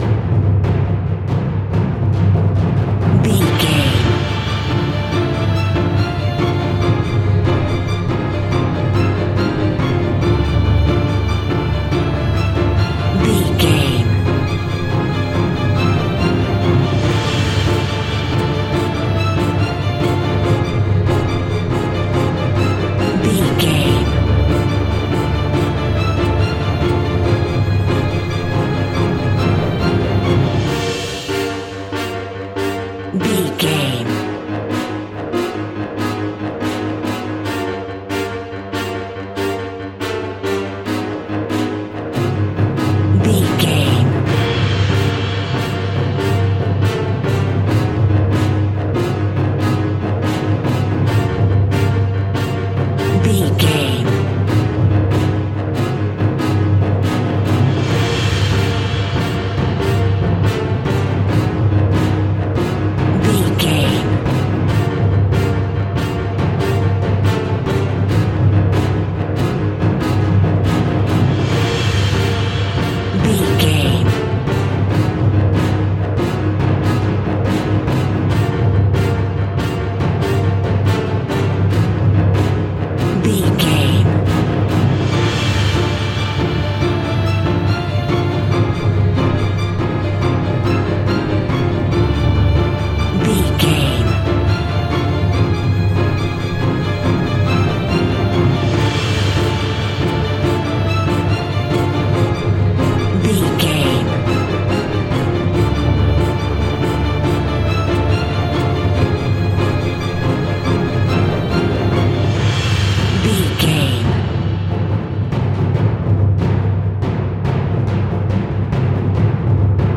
Action Explosion Music.
Aeolian/Minor
D
Fast
scary
tension
ominous
dark
dramatic
strings
drums
brass
orchestra
synth